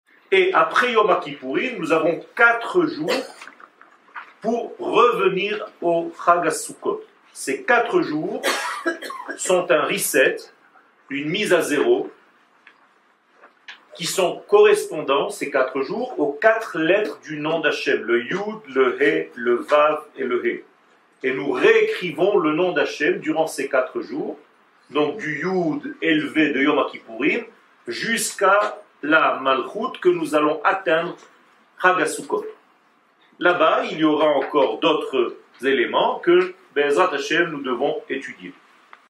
Les 4 jours avant la fête de souccot 00:00:40 Les 4 jours avant la fête de souccot שיעור מ 06 אוקטובר 2022 00MIN הורדה בקובץ אודיו MP3 (611.97 Ko) הורדה בקובץ וידאו MP4 (1.96 Mo) TAGS : שיעורים קצרים